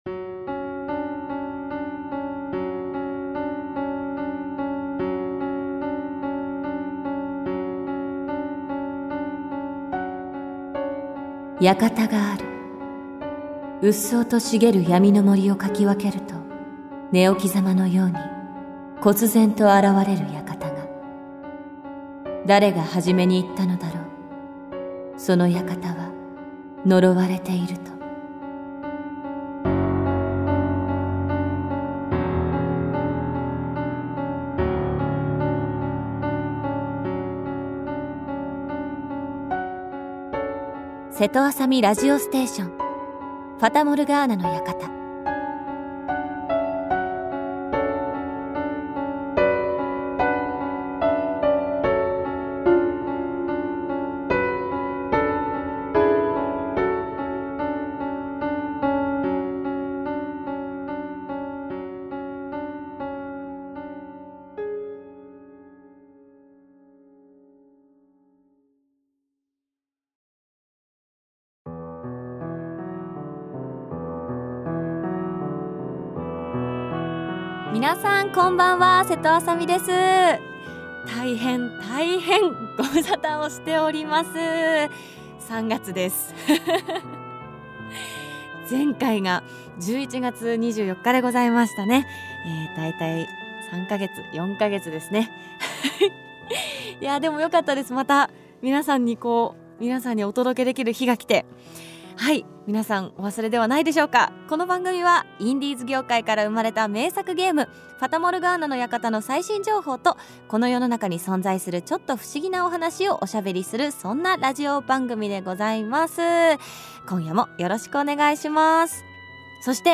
この番組はインディーズ業界から生まれた人気ゲーム『ファタモルガーナの館』の最新情報とこの世の中に存在する『ちょっと不思議なお話』をパーソナリティの瀬戸麻沙美さんがゆったりおしゃべりいたします。